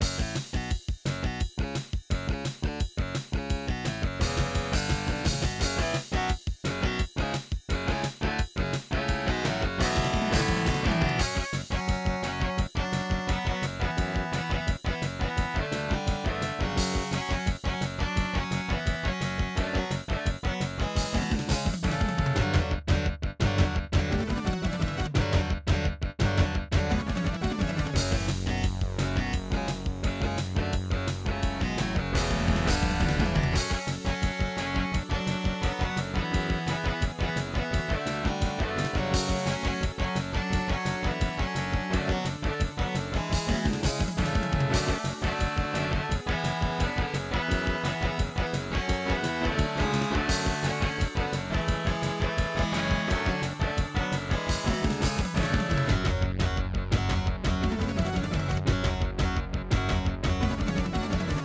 Some records contain clicks.